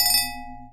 chime_bell_02.wav